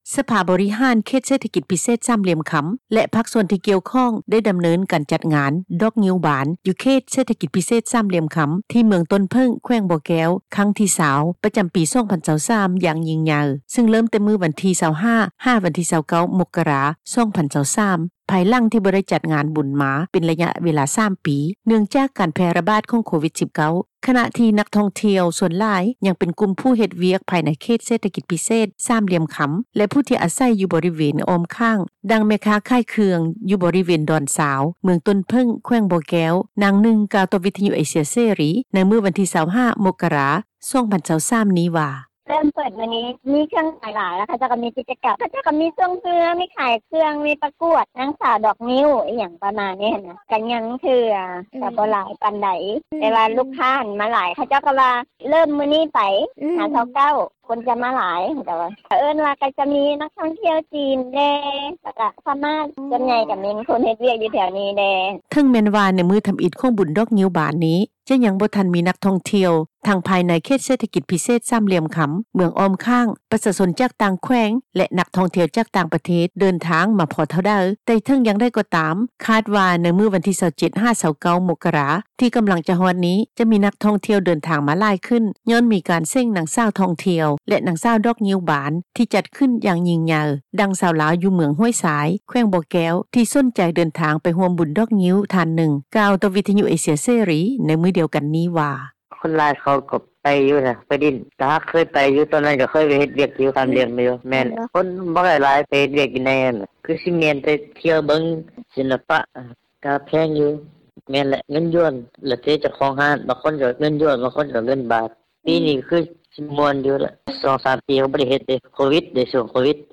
ດັ່ງແມ່ຄ້າຂາຍເຄື່ອງ ຢູ່ບໍຣິເວນດອນຊາວ ເມືອງຕົ້ນເຜິ້ງ ແຂວງບໍ່ແກ້ວ ນາງນຶ່ງ ກ່າວຕໍ່ ວິທຍຸ ເອເຊັຽເສຣີ ໃນມື້ວັນທີ 25 ມົກຣາ 2023 ນີ້ວ່າ:
ດັ່ງ ຊາວລາວຢູ່ເມືອງຫ້ວຍຊາຍ ແຂວງບໍ່ແກ້ວ ທີ່ສົນໃຈເດີນທາງໄປຮ່ວມບຸນດອກງິ້ວບານ ທ່ານນຶ່ງ ກ່າວຕໍ່ ວິທຍຸ ເອເຊັຽເສຣີ ໃນມື້ດຽວກັນນີ້ວ່າ:
ດັ່ງ ຄົນງານລາວ ທີ່ເຮັດວຽກຢູ່ພາຍໃນເຂດເສຖກິຈ ສາມຫລ່ຽມຄໍາ ທ່ານນຶ່ງກ່າວວ່າ: